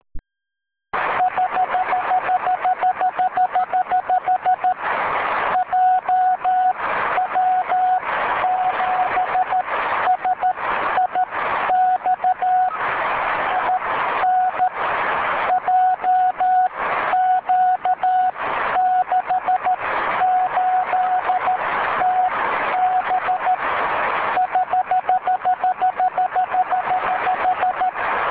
JW7SIX/bcn Hear his Signal in Rome , OX3SIX/bcn + JX7DFA (South Italy), K (I2 area) Tep TR.